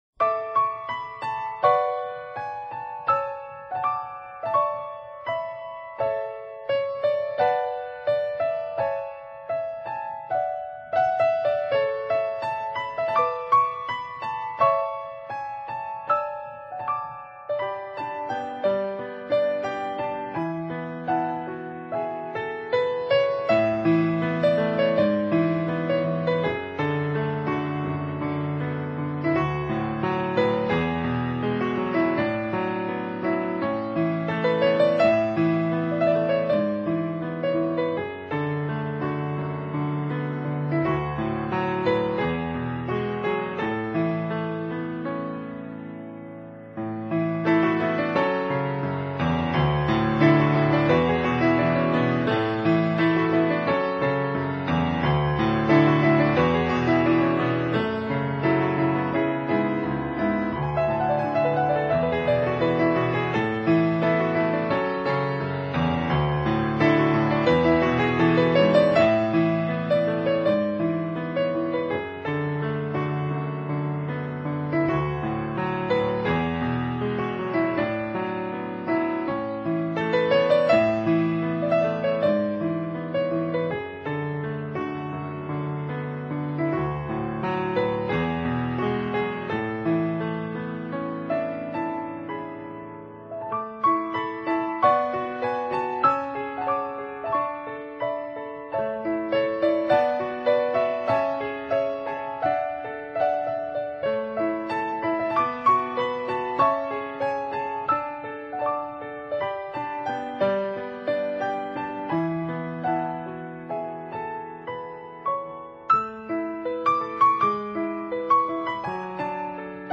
音乐类型：NewAge 新世纪
音乐风格：Piano